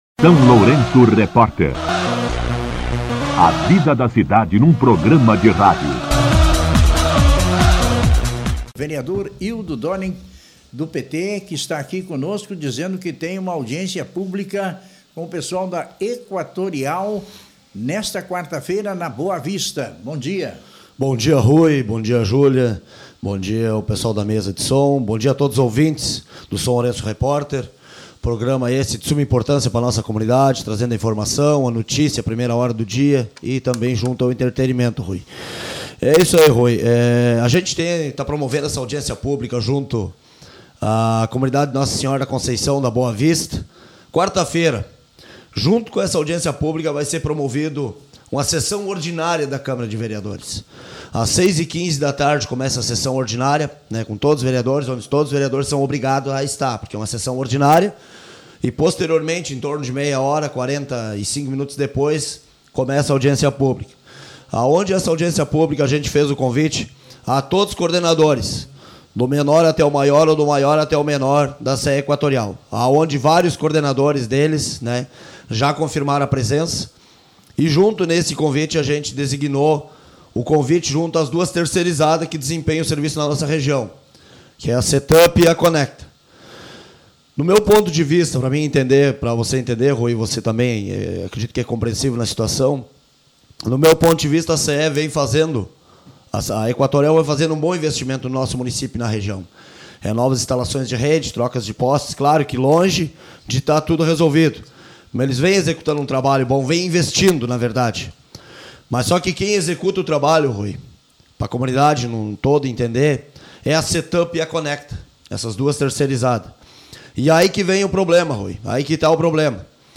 O vereador Ildo Döring (PT) concedeu entrevista à SLR RÁDIO nesta segunda-feira (27) para convidar a comunidade a participar da Audiência Pública que será realizada na Comunidade Nossa Senhora da Conceição da Boa Vista, nesta quarta-feira, 29 de outubro, durante Sessão Ordinária, a partir das 18h15.
Entrevista com O vereador Ildo Döring